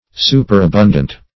Superabundant \Su`per*a*bun"dant\, a. [L. superabundans, p. pr.